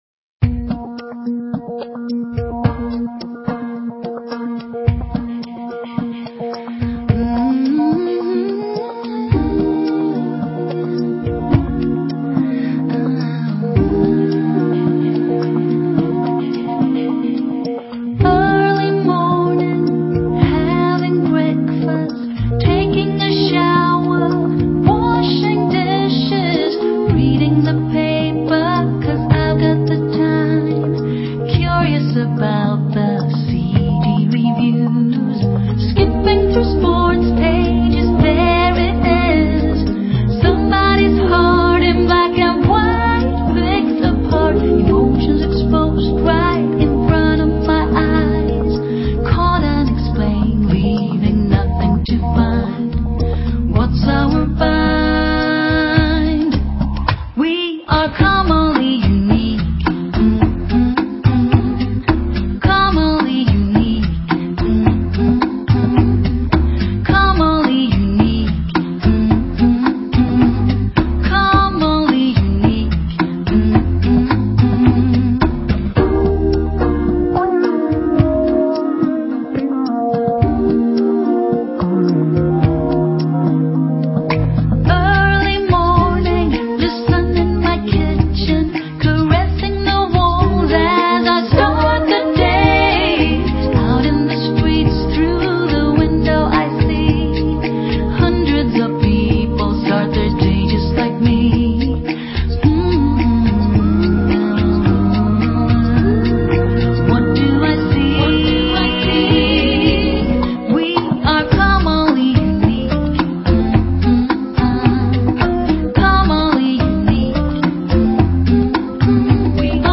Vocal jazz